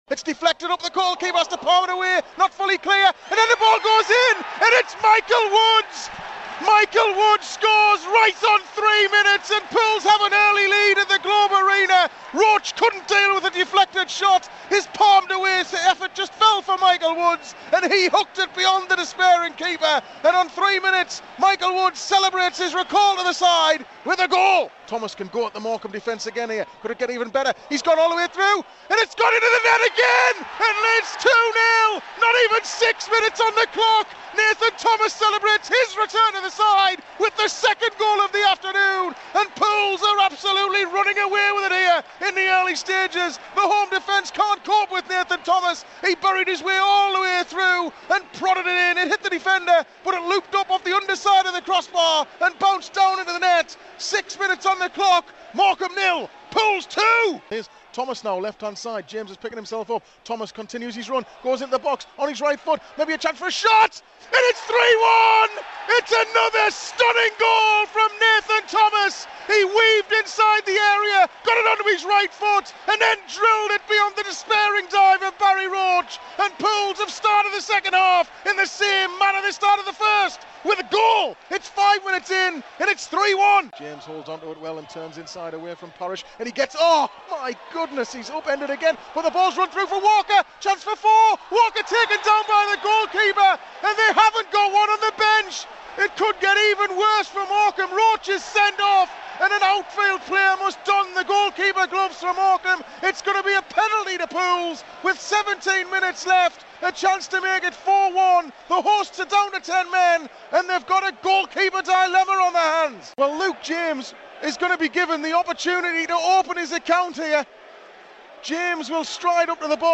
Listen back all the key moments from Saturday's brilliant win as they happened live on Pools PlayerHD.